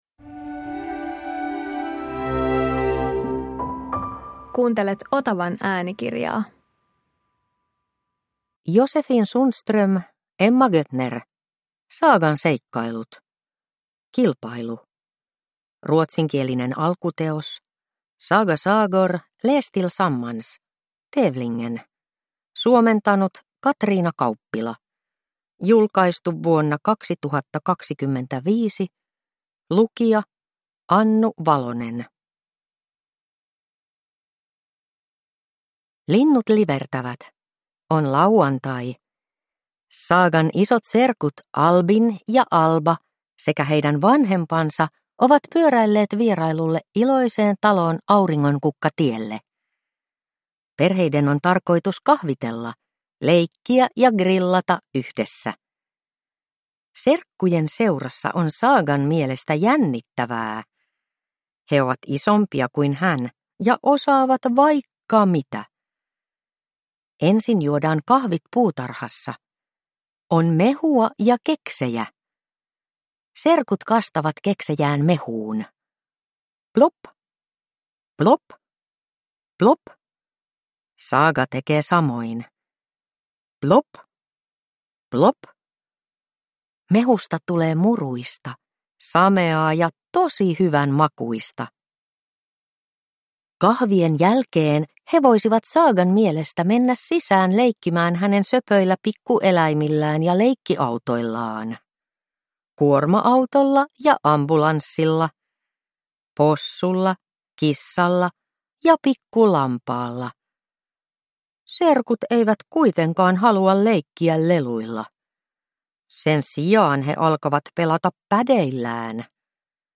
Saagan seikkailut. Kilpailu – Ljudbok